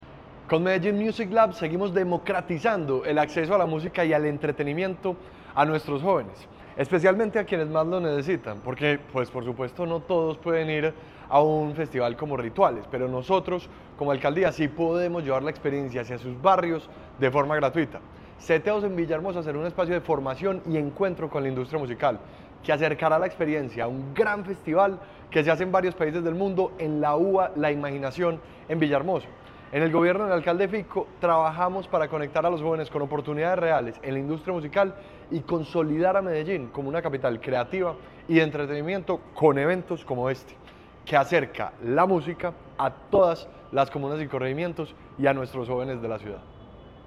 Declaraciones secretario de la Juventud, Ricardo Jaramillo La Alcaldía de Medellín y Ritvales, el festival de electrónica más grande del país, estarán este viernes 31 de octubre, entre la 1:00 p. m. y las 12:30 de la medianoche, en la UVA de la Imaginación.
Declaraciones-secretario-de-la-Juventud-Ricardo-Jaramillo.mp3